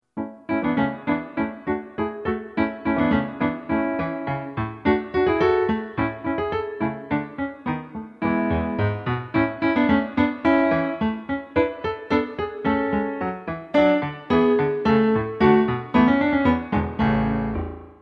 A Ballet Class CD